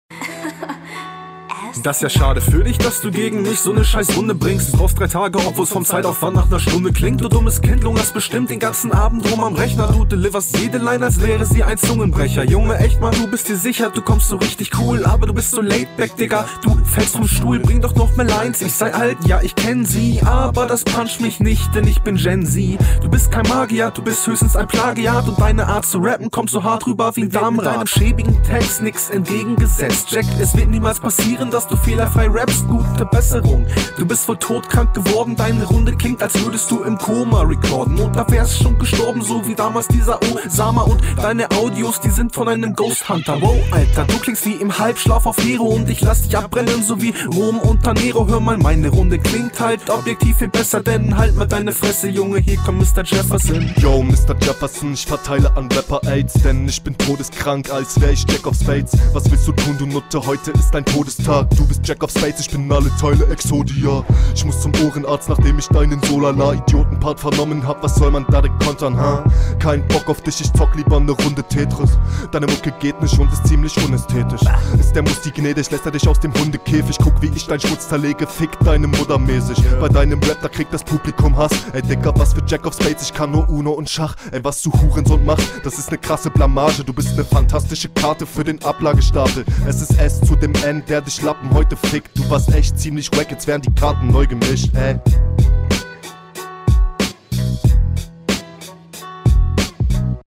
viel besserer flow, mit krasserer reimsetzung, riesen steigerung, deutlich besser als die erste runde hier …
Gute Runde aber hier auch wieder die Abmische :)